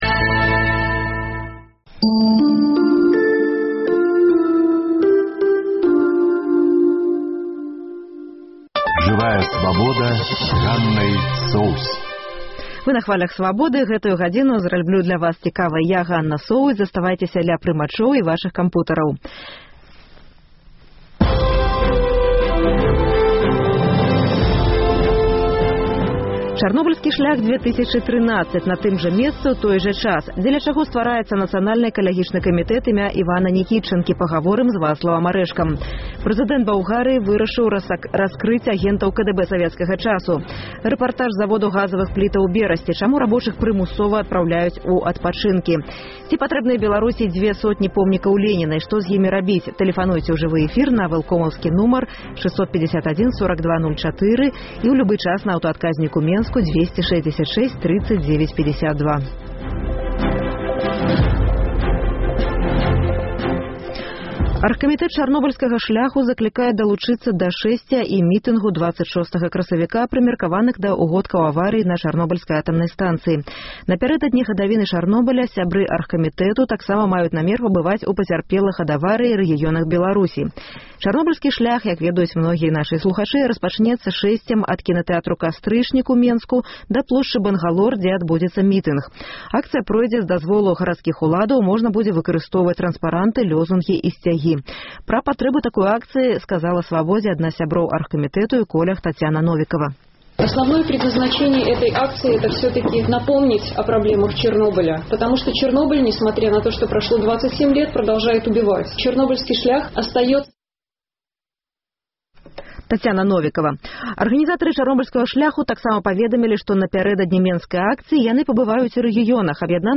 Рэпартаж з заводу «Брэстгазаапарат», дзе рабочых прымусова адпраўляюць у адпачынкі.